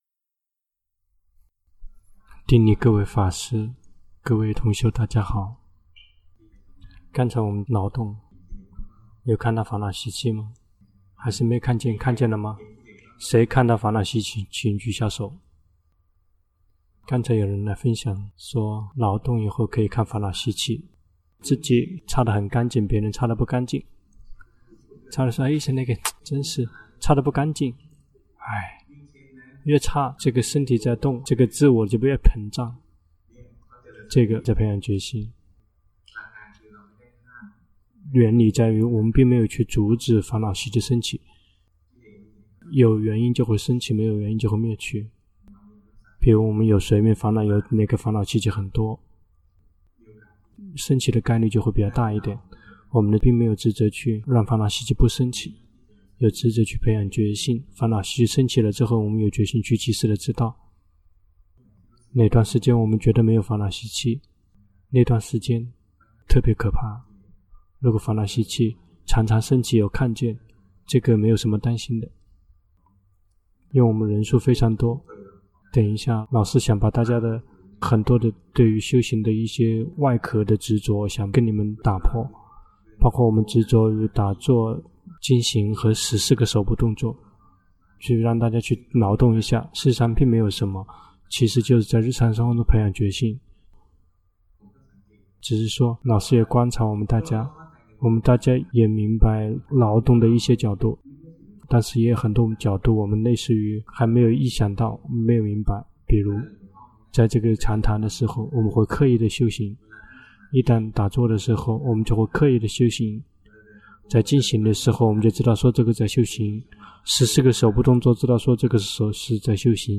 第十二屆泰國四念處禪修課程 課程現場翻譯